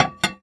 R - Foley 32.wav